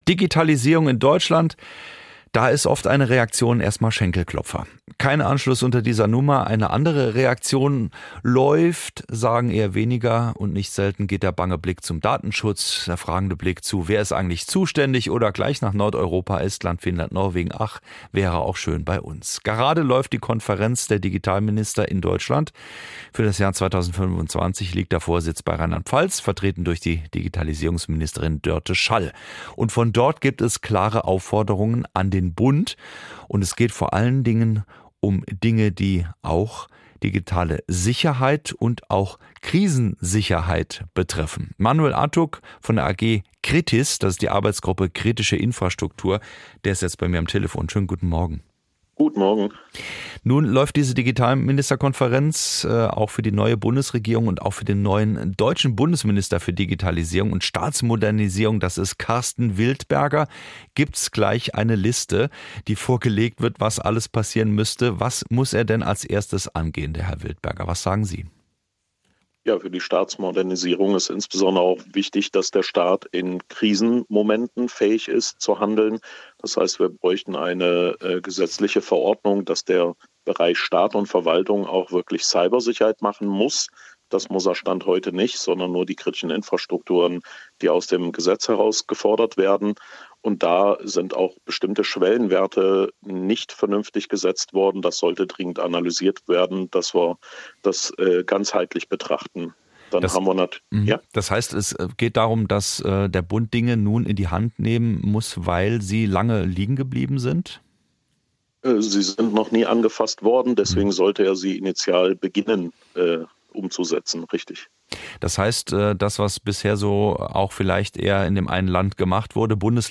Mit freundlicher Genehmigung vom Saarländischen Rundfunk hier zum Nachhören.